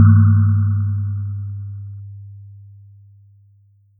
Play, download and share boop original sound button!!!!
boop.mp3